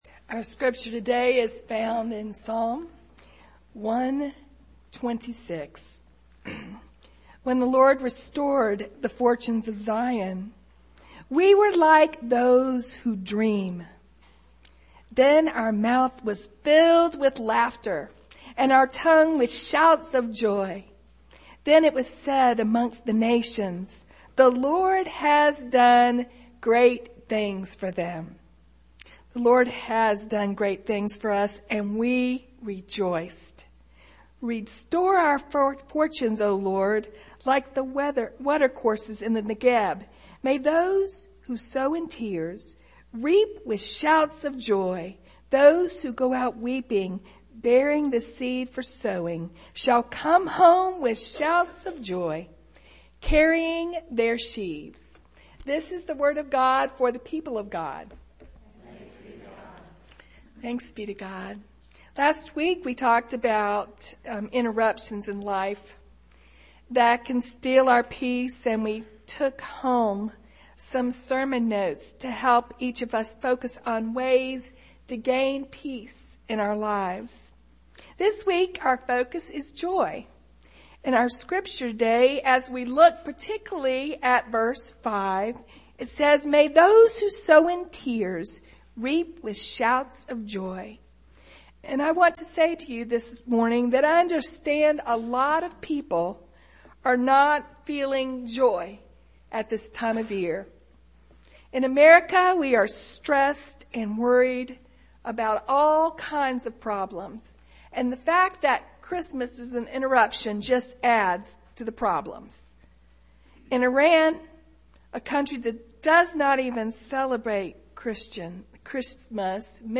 Chatsworth First Methodist Church Sermons